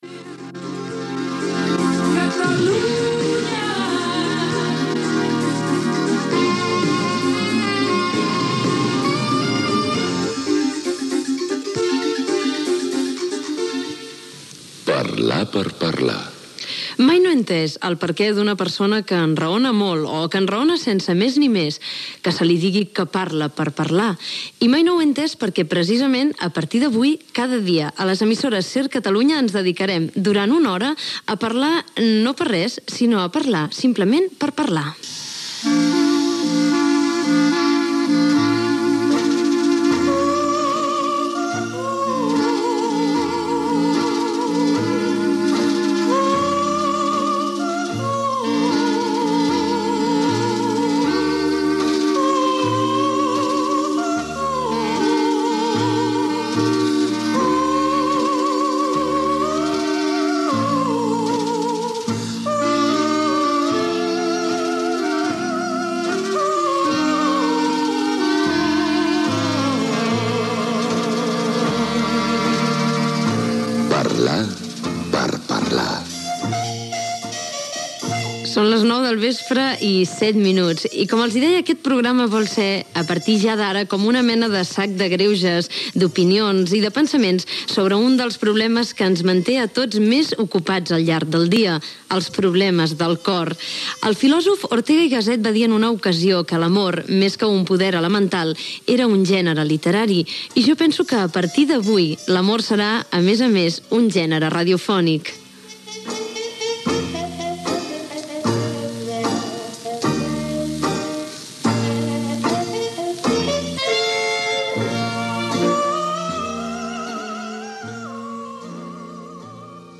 Indicatiu de l'emissora, presentació del primer programa, sintonia, indentificació, hora, objectiu del programa